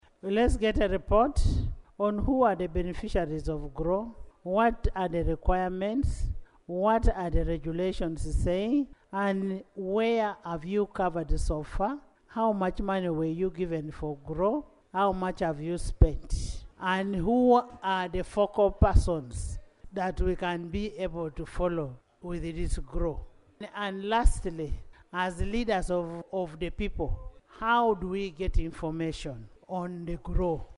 She made the remarks in her communication during the plenary sitting on Monday, 14 October 2024.
AUDIO Speaker Among